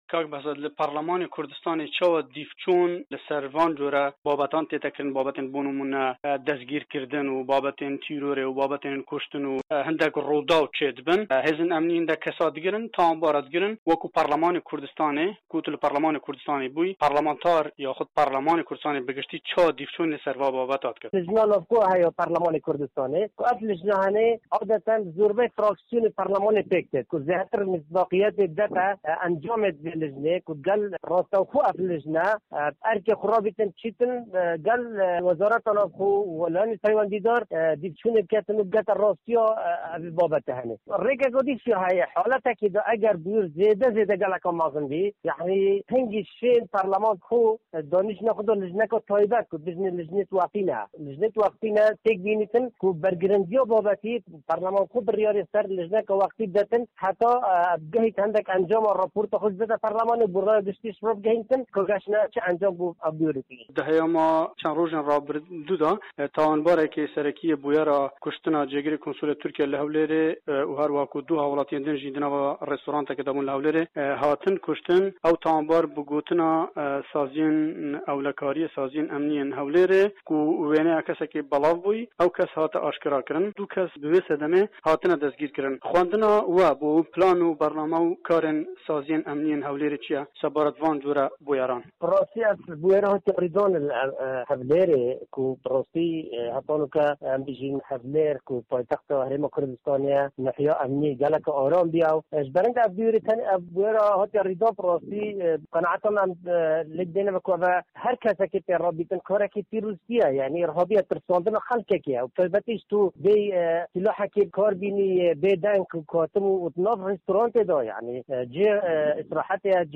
وتووێژی به‌هزاد ده‌روێش